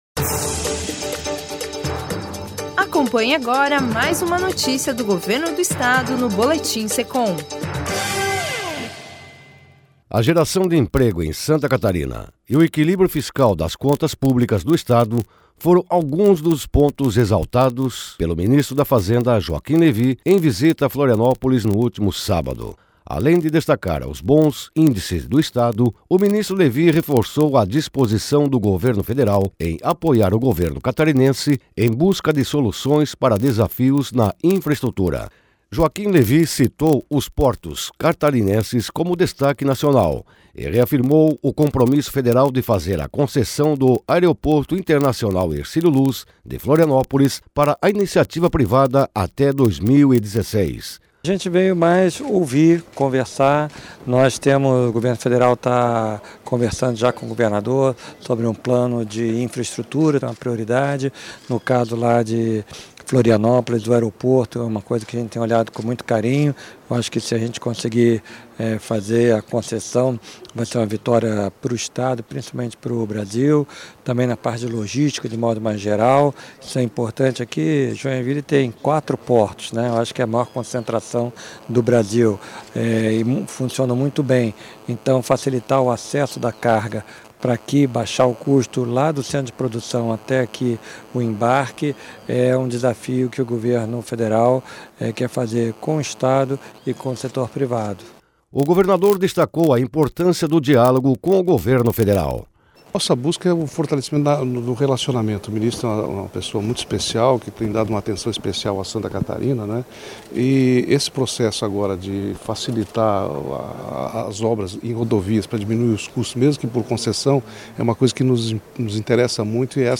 O ministro Joaquim Levy visitou Florianópolis no último sábado, onde proferiu uma palestra e concedeu entrevista./